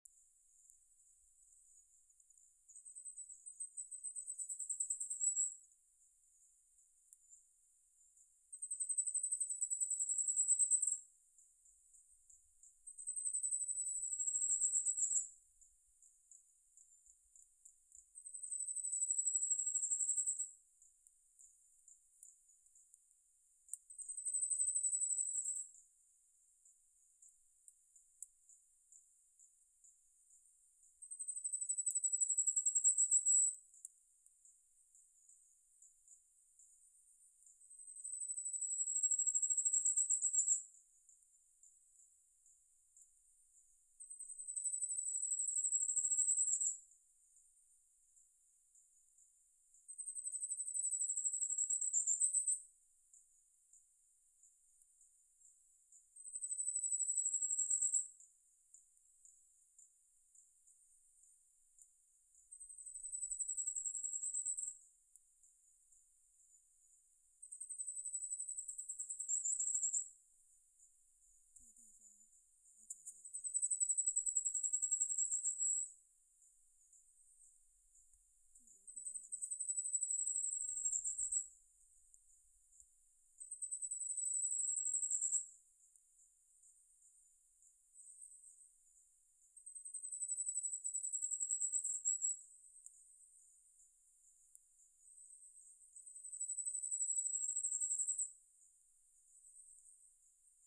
48-4大鐵杉2012mar26火冠戴菊1.mp3
火冠戴菊鳥 Regulus goodfellowi
南投縣 信義鄉 塔塔加
5.5 錄音環境 人工林邊緣 發聲個體 行為描述 鳥叫 錄音器材 錄音: 廠牌 Denon Portable IC Recorder 型號 DN-F20R 收音: 廠牌 Sennheiser 型號 ME 67 標籤/關鍵字 備註說明 MP3檔案 48-4大鐵杉2012mar26火冠戴菊1.mp3